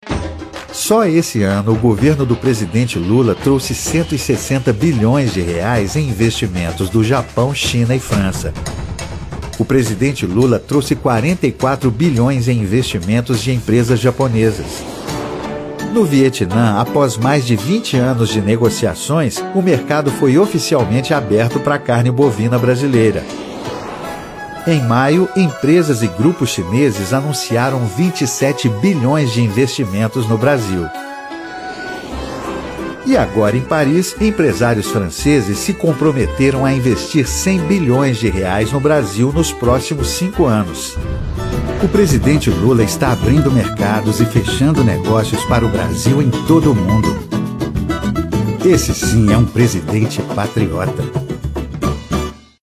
• SPOT